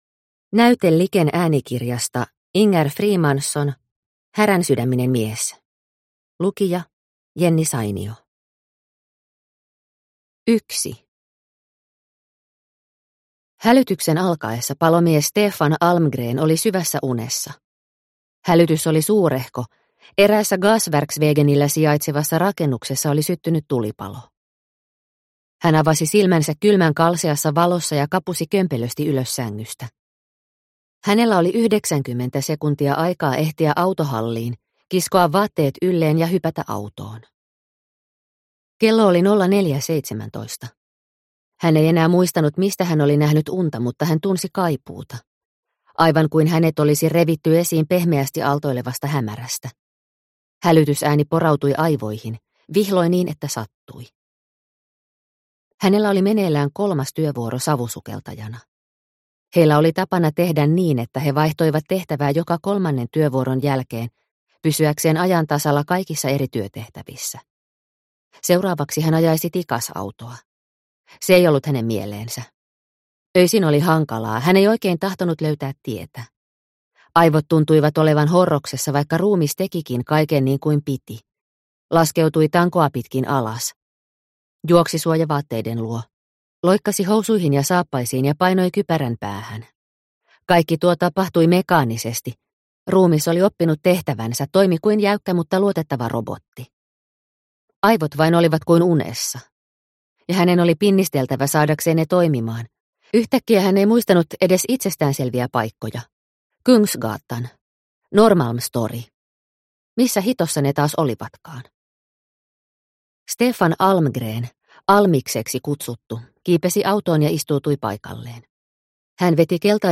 Häränsydäminen mies – Ljudbok – Laddas ner